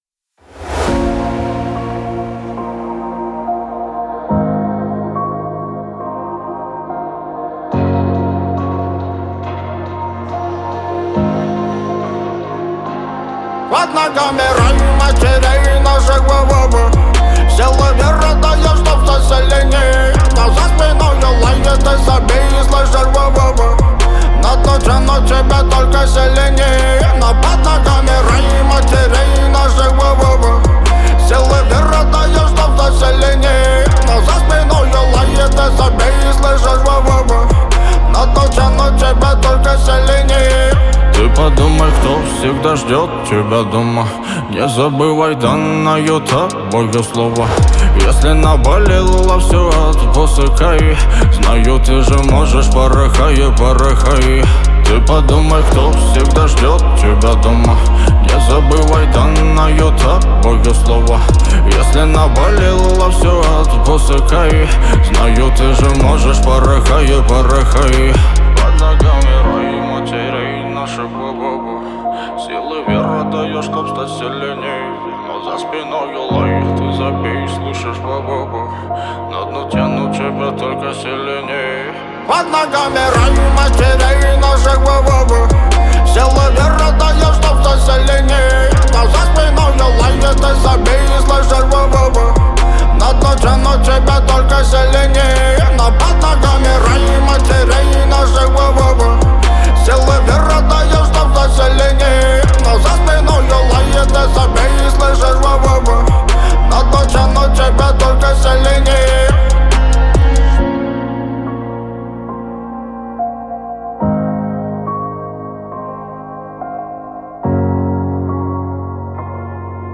это песня в жанре фолк-рок